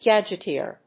音節gad・ge・teer 発音記号・読み方
/gædʒɪtíɚ(米国英語), gædʒɪtíə(英国英語)/